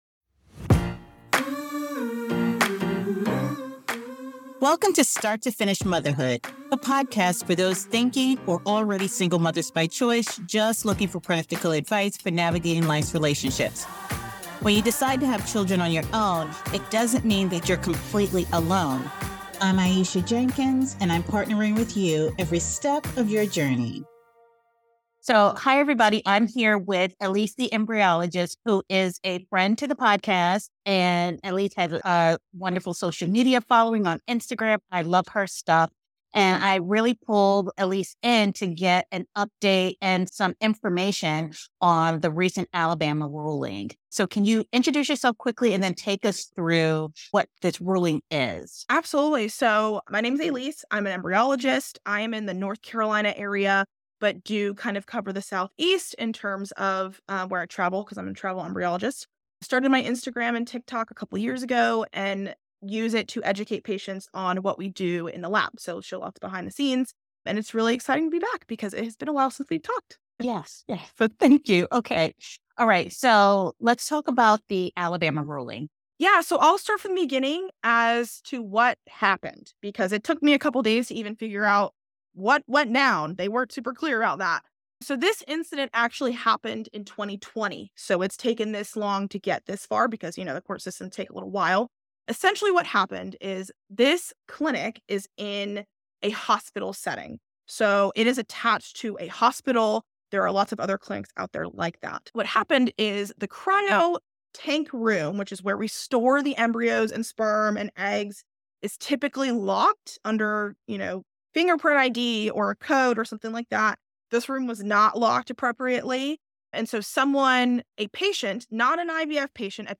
This conversation offers valuable insights into the intersection of reproductive technology, law, and ethics.